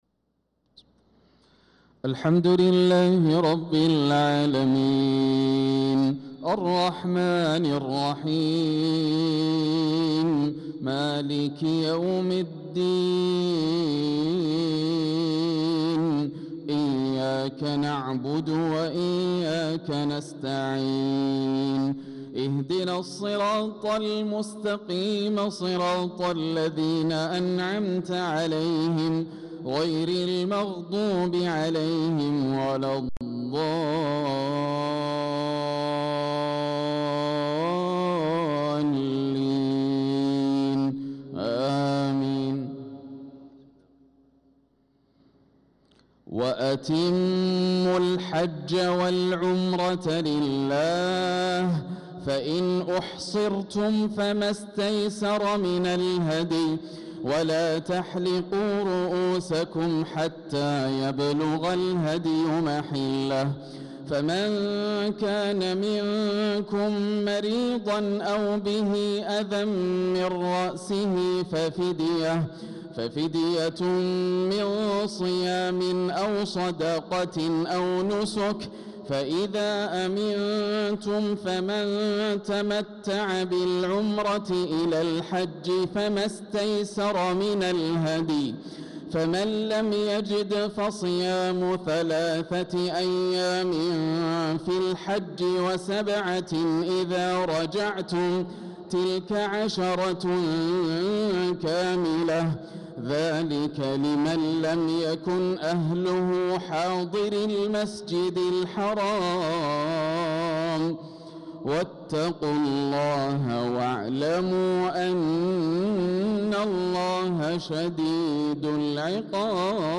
صلاة العشاء للقارئ ياسر الدوسري 8 ذو الحجة 1445 هـ